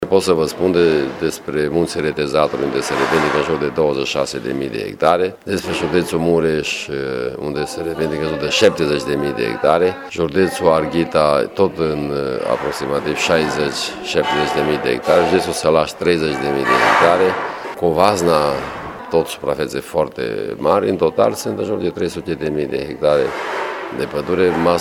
Una dintre problemele importante dezbătute în această după-masă la Universitatea de Vară de la Izvoru Mureşului este cea legată de procesele de retrocedare a proprietăţilor.